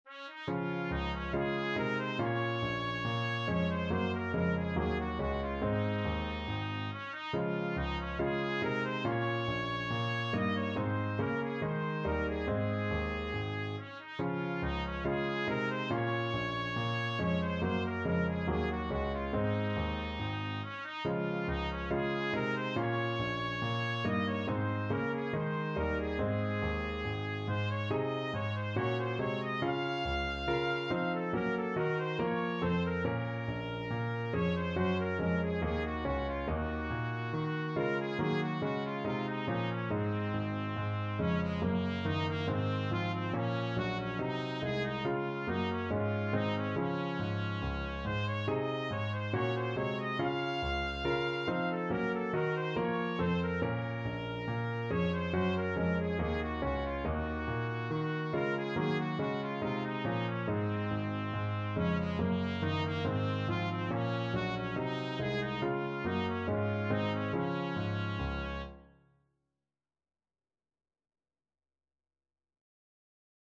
4/4 (View more 4/4 Music)
Ab4-F6
Classical (View more Classical Trumpet Music)